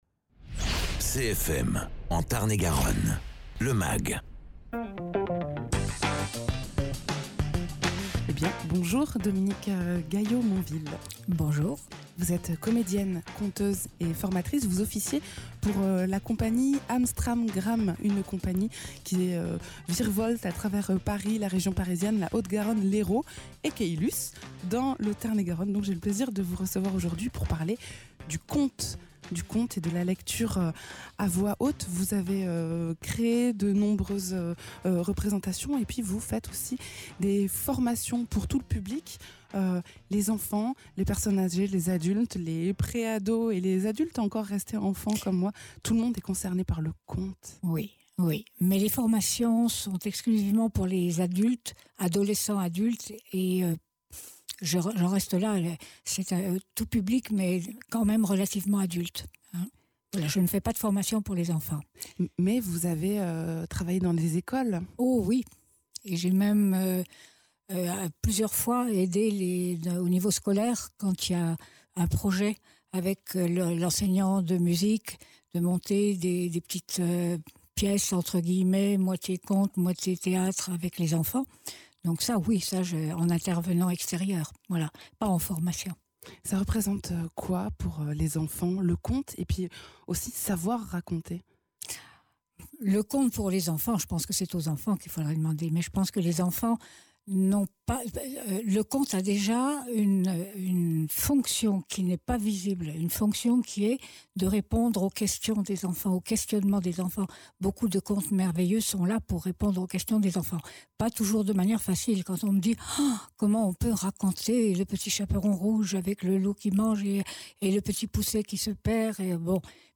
conteuse